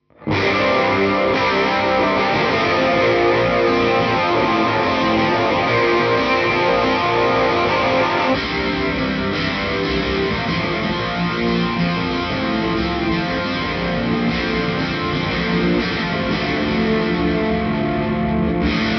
No EQ, no compression, no reverb, just the guitar plugged into the audio interface.
In the first part of the audio sample you hear the Deluxe1 and in the second part you hear the TwinR.
Heavy Tone
C2 (chorus)